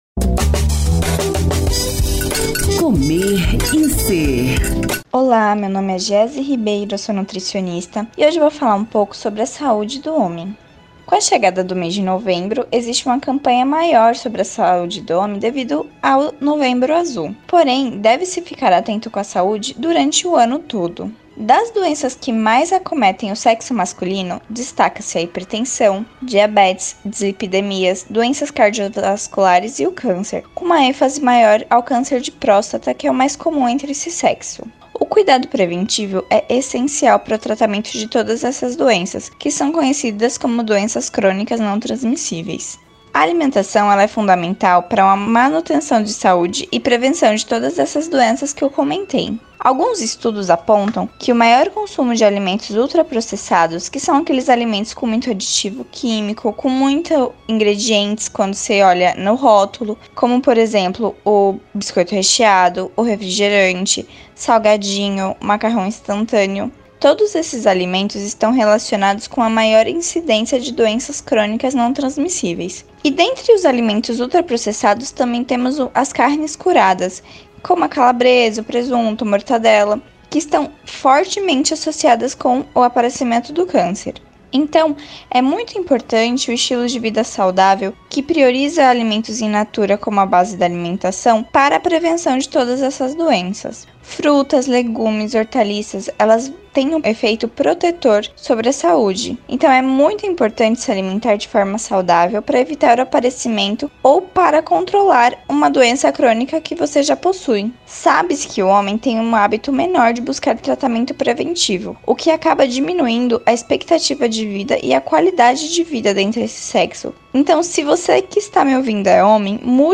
O assunto foi tema do quadro “Comer e Ser”, veiculado às Segundas-feiras pelo programa Saúde no ar, com transmissão pelas Rádios Excelsior AM 840  e  Web Saúde no ar.